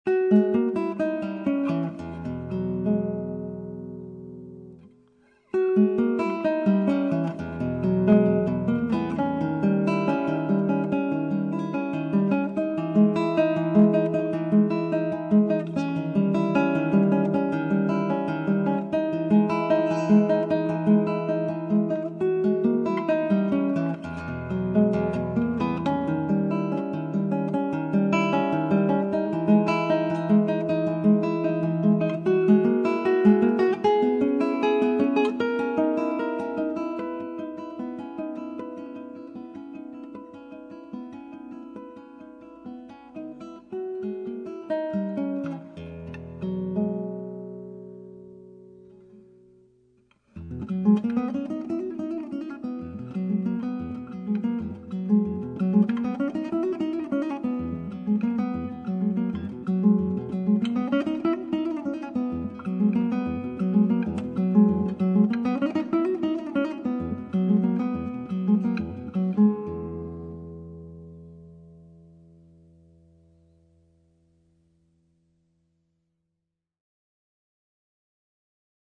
6 cordes
Enregistré à la Salle "Le Royal" de Pessac (Gironde)